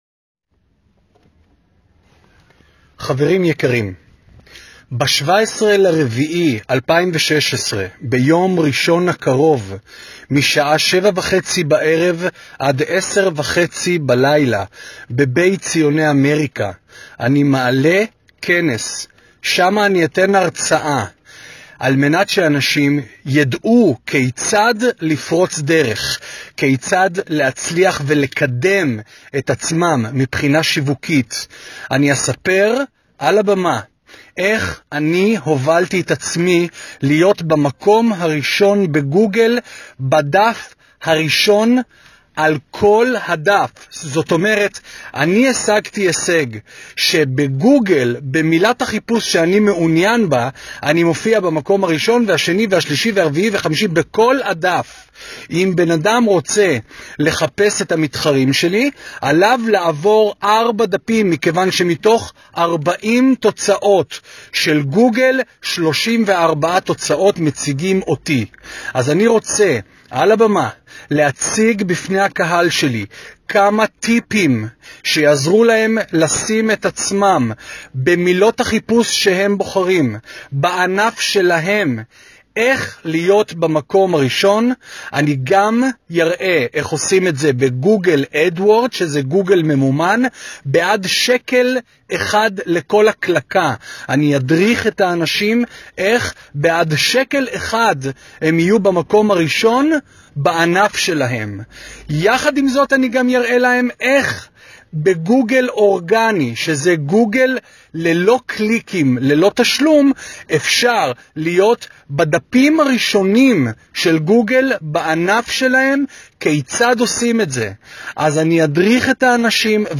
רוב ההקלטות ב-"תחנת הרדיו הפרטית" בוצעו במכשיר הסמארטפון והועלו לכאן ללא כל עריכה, וכך גם אתה יכול להקליט את המסר שלך, ללחוץ עוד קליק או 2, ולשדר את עצמך והמסר שלך - לעולם!